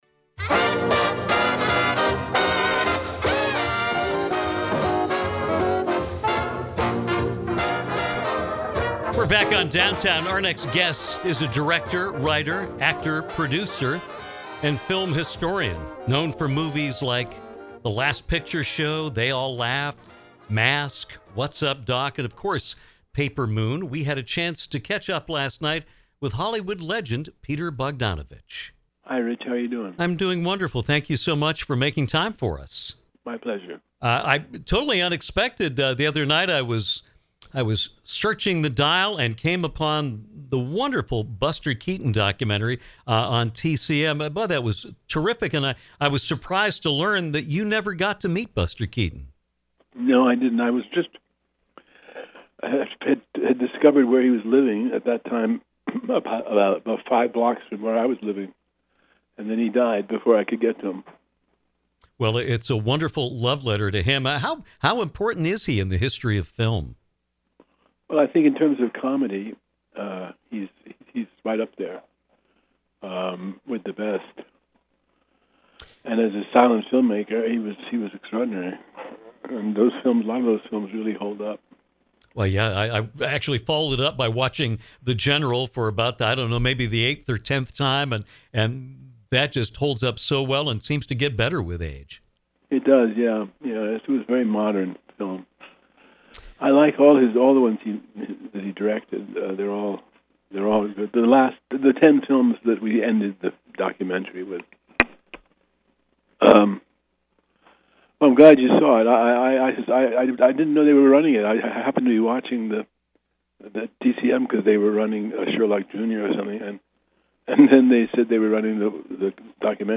Director, actor, and film historian Peter Bogdanovich joined us to discuss his remarkable career an share his thoughts on people like Orson Welles, John Ford, Ryan O’Neal, Cher, and John Ritter.